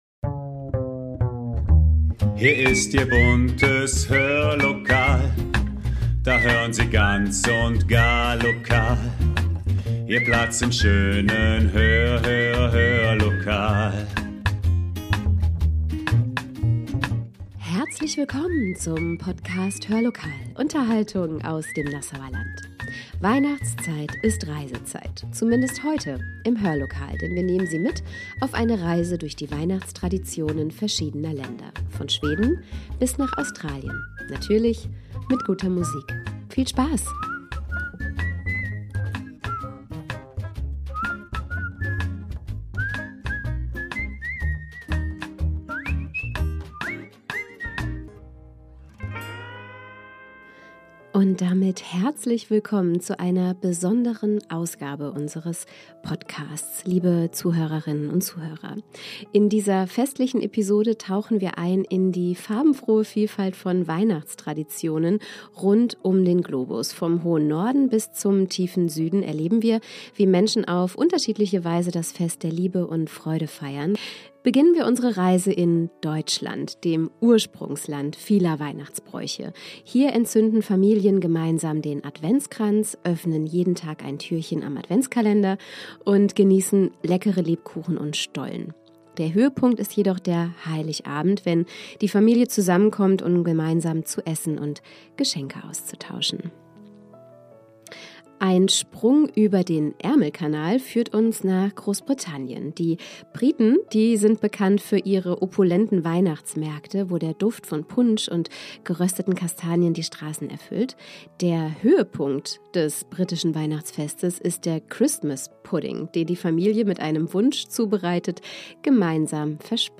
... zumindest heute im Hörlokal – denn wir nehmen Sie mit auf eine Reise durch die Weihnachtstraditionen verschiedener Länder - von Schweden bis nach Australien - und natürlich mit guter Musik.